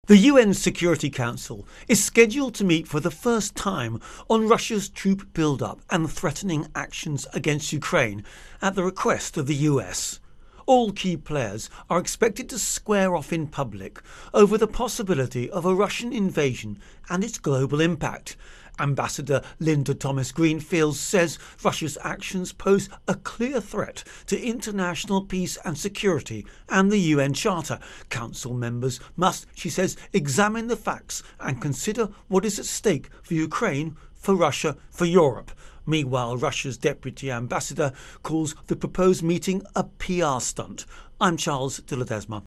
United Nations-US-Russia-Ukraine Intro and Voicer